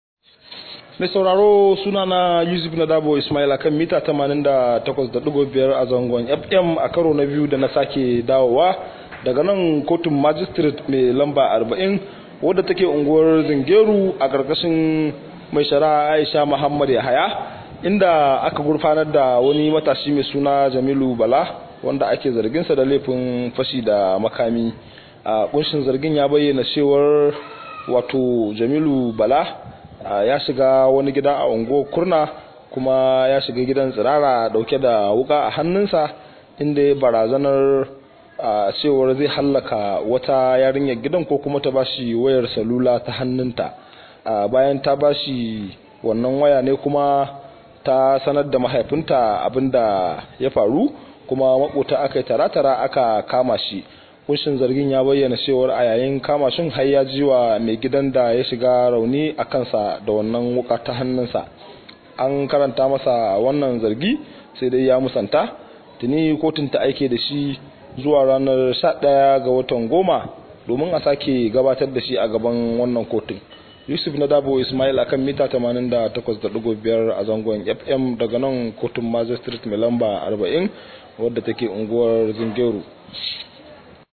Rahoto: Matashi ya gurfana a kotu kan zargin fashi da makami